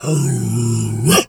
bear_roar_03.wav